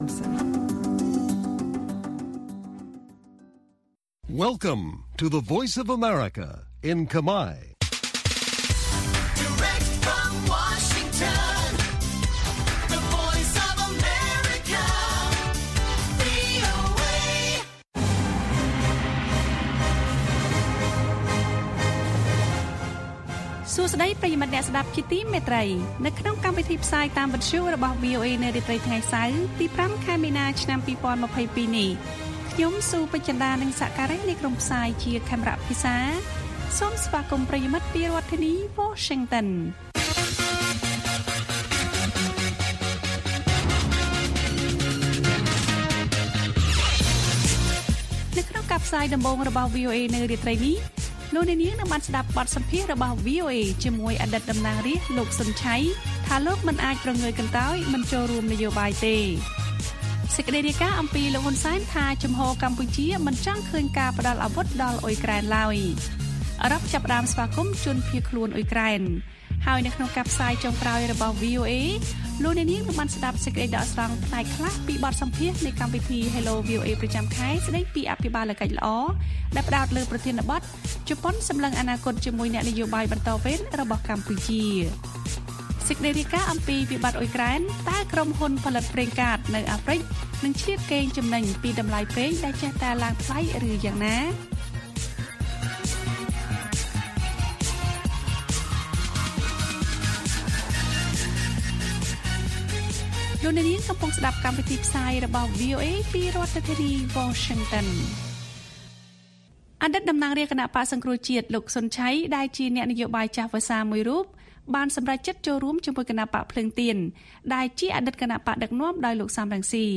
ព័ត៌មាននៅថ្ងៃនេះមាន បទសម្ភាសន៍៖ អតីតតំណាងរាស្រ្តលោក សុន ឆ័យ ថា លោកមិនអាចព្រងើយកន្តើយមិនចូលរួមនយោបាយទេ។ សេចក្តីដកស្រង់ពីកម្មវិធី Hello VOA ស្តីពី«អ្នកឃ្លាំមើលថា ជប៉ុនផ្ដើមស្ទាបស្ទង់ធ្វើការជាមួយអ្នកនយោបាយបន្តវេនកម្ពុជា»។ អឺរ៉ុប ចាប់ផ្តើមស្វាគមន៍ជនភៀសខ្លួនអ៊ុយក្រែន និងព័ត៌មានផ្សេងទៀត៕